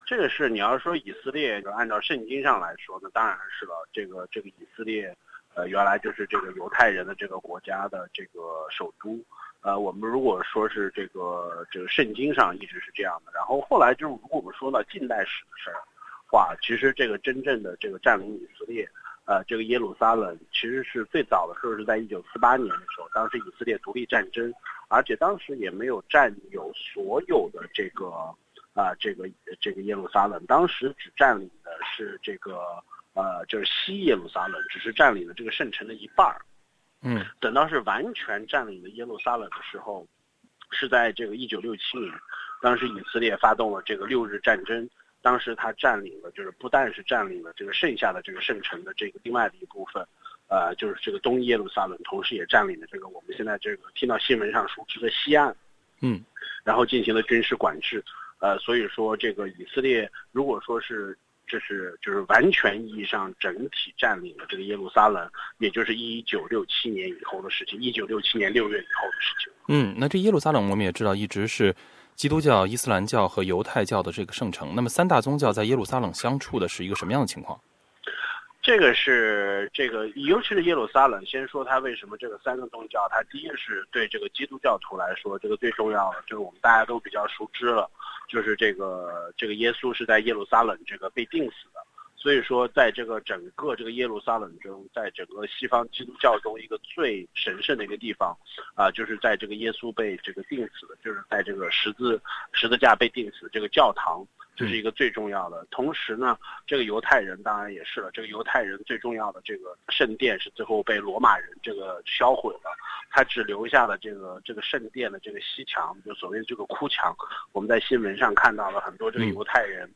(AAP) Source: AAP SBS 普通话电台 View Podcast Series Follow and Subscribe Apple Podcasts YouTube Spotify Download (3.33MB) Download the SBS Audio app Available on iOS and Android 联合国大会以压倒性多数票通过谴责美国承认耶路撒冷为以色列首都的提案。